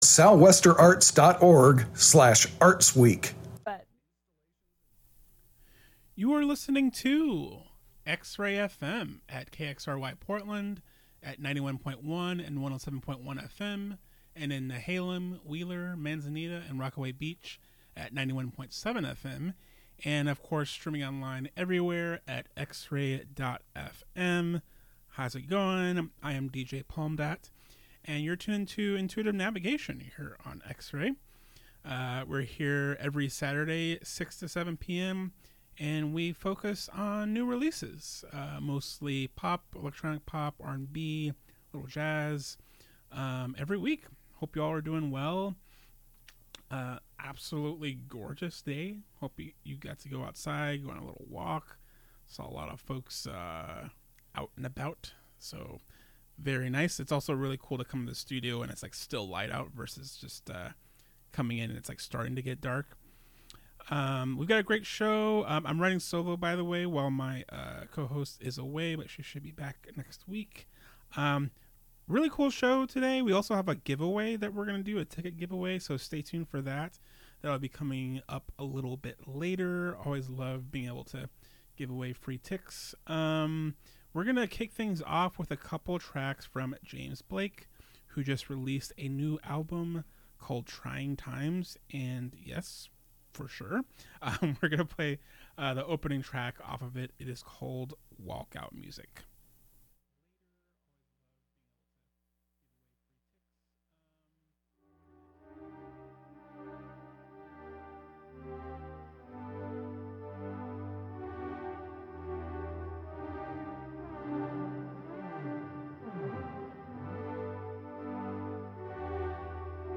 The latest in electronic pop, R&B, and other soulful music.
Feels-talk, moon-talk, and sultry jams.